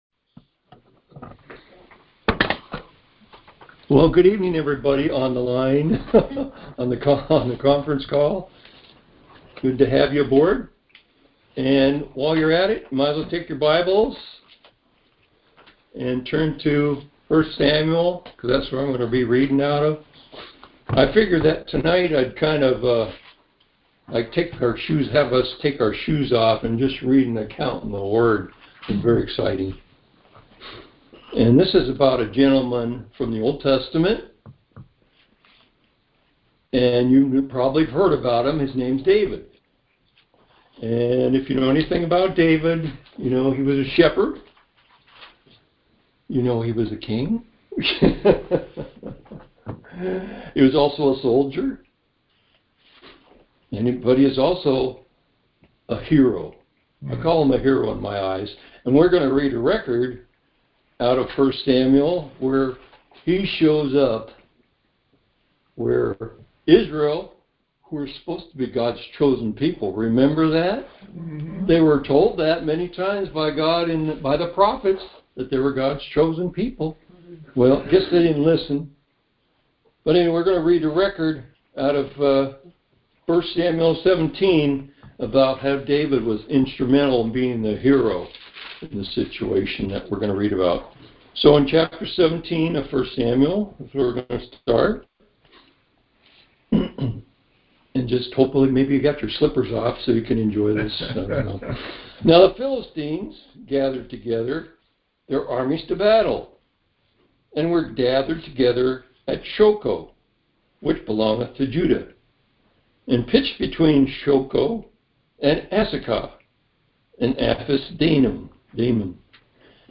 Conference Call Fellowship